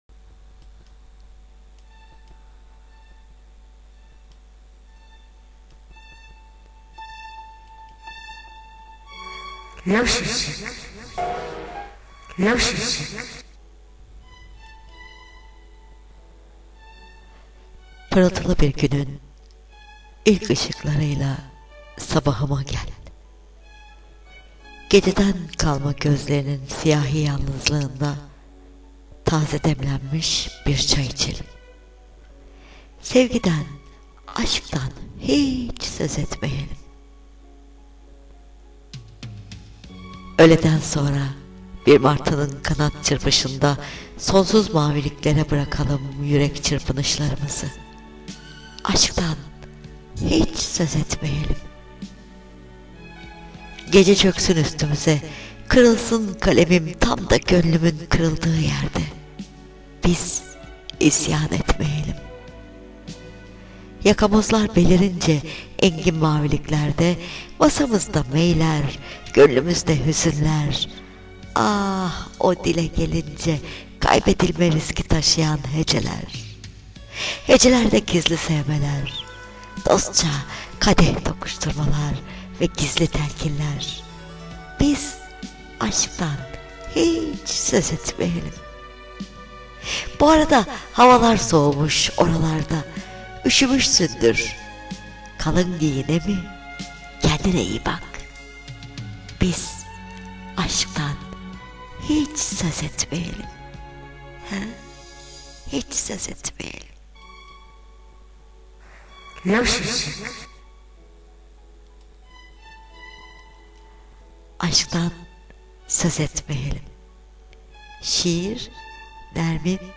AŞKTAN HİÇ SÖZ ETMIYELIM N.MERCAN KALEMINDEN sesli şiiri